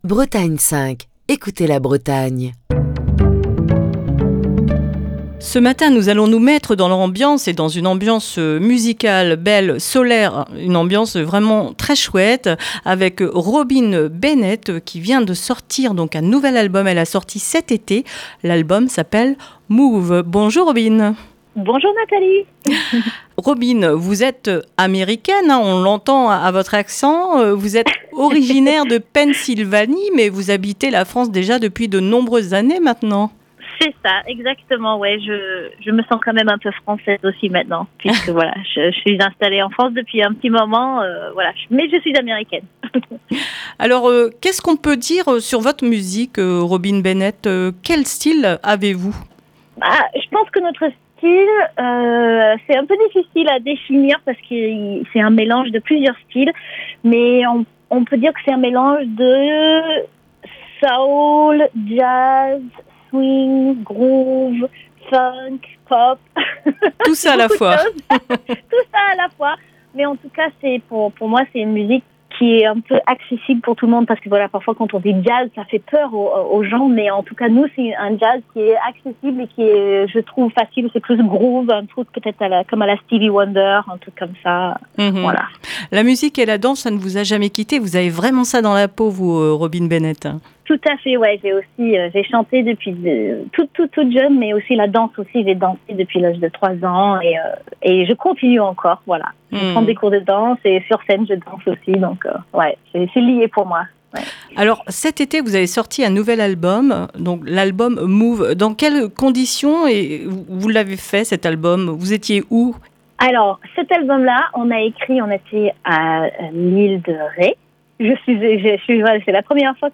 Ce vendredi dans le coup de fil du matin